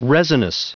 Prononciation du mot resinous en anglais (fichier audio)
Prononciation du mot : resinous